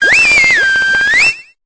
Cri d'Oratoria dans Pokémon Épée et Bouclier.